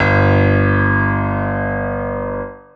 Key-piano-02.wav